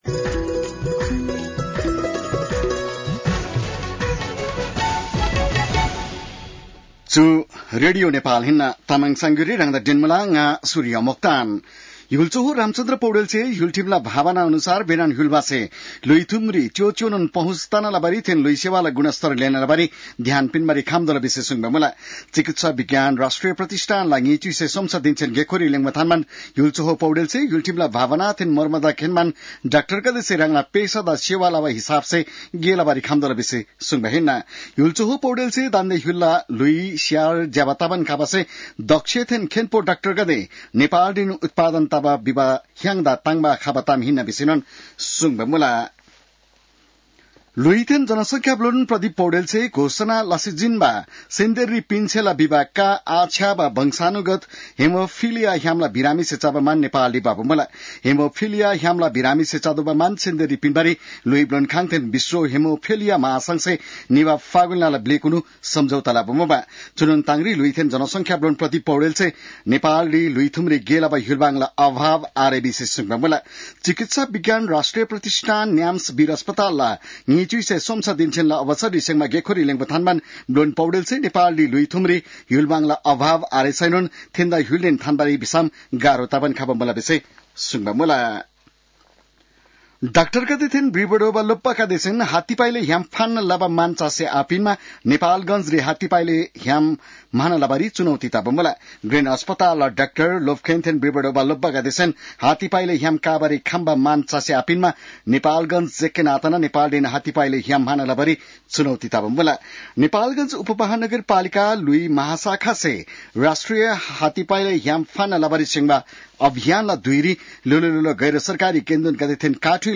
तामाङ भाषाको समाचार : २३ वैशाख , २०८२